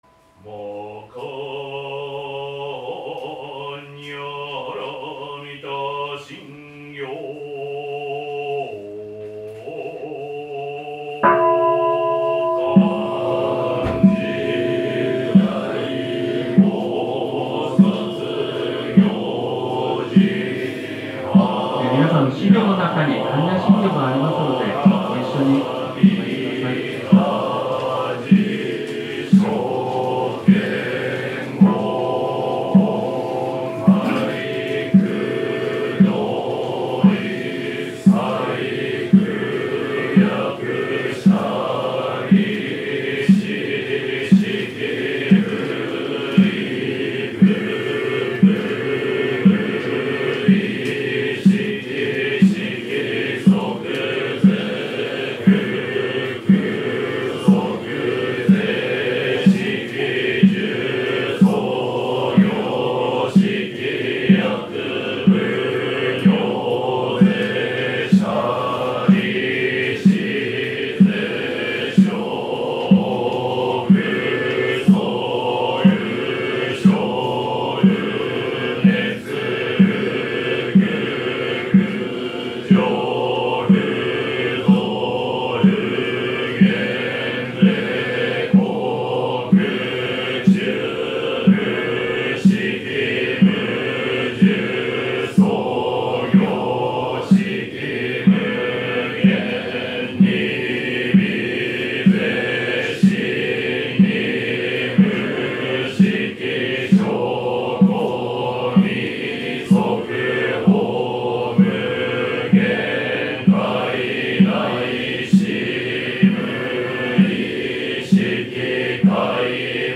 当山にて、令和7年度群馬県第13教区の「特派布教」が開催されました。
開講式の般若心経